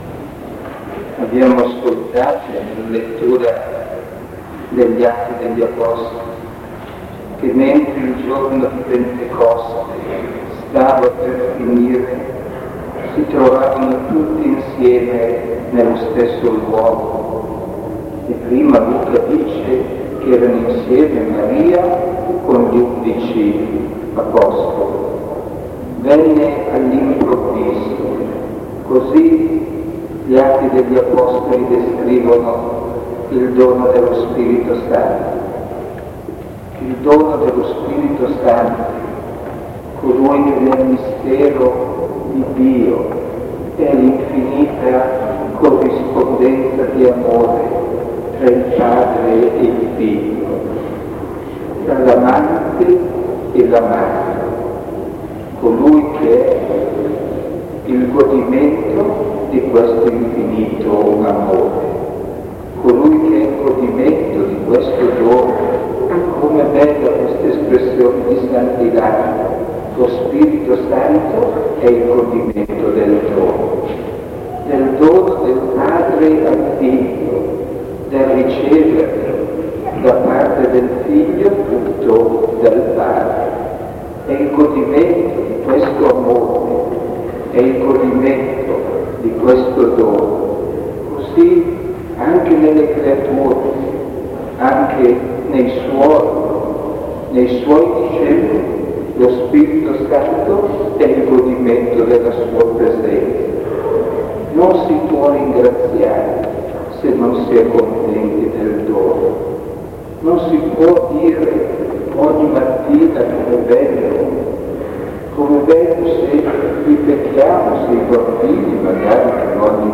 OMELIA